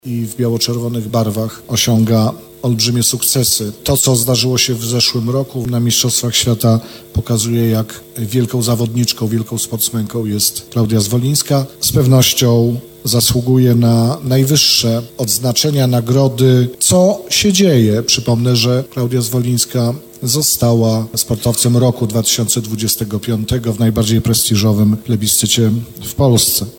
– Klaudia Zwolińska swoimi sukcesami sławi Sądecczyznę, ale i całą Polskę – mówił przewodniczący Rady Miasta Nowego Sącza Krzysztof Głuc.